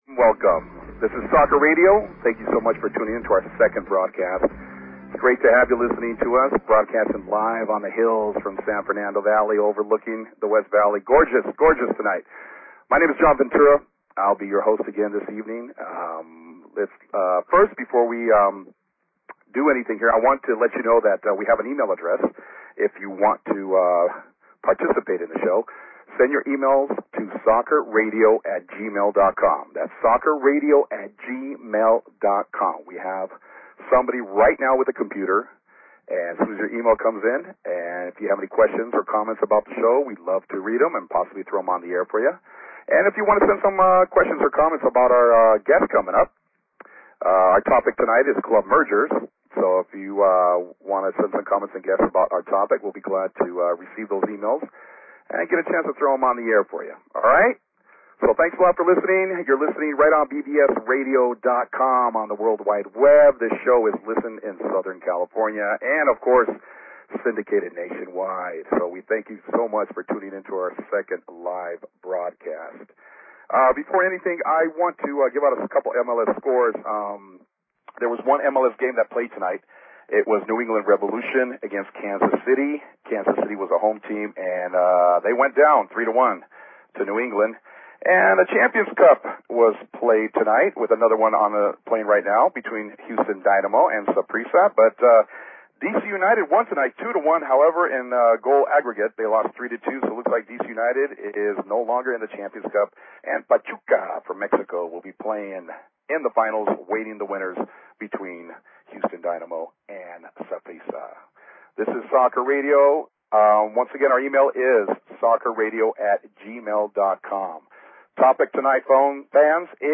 Talk Show Episode, Audio Podcast, Soccer_Radio and Courtesy of BBS Radio on , show guests , about , categorized as